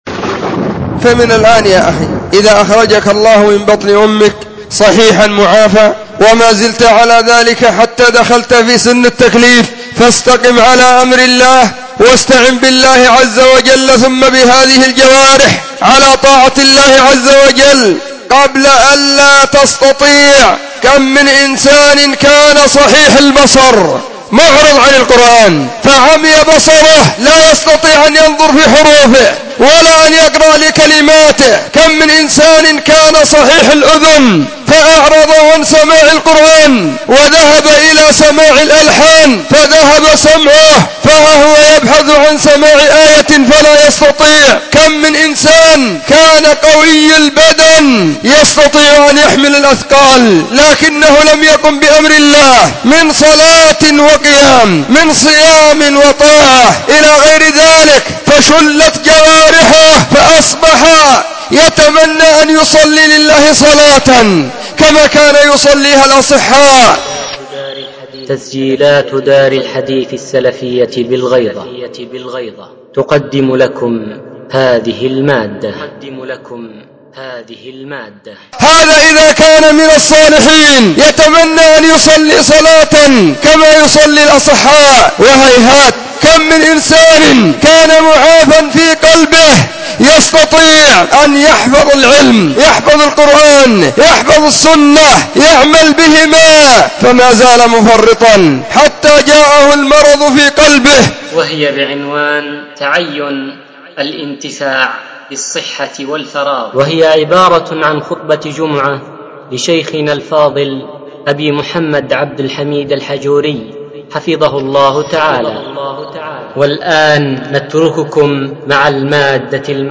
📢 وكانت في مسجد الصحابة بالغيضة محافظة المهرة – اليمن.